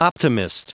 Prononciation du mot optimist en anglais (fichier audio)
Prononciation du mot : optimist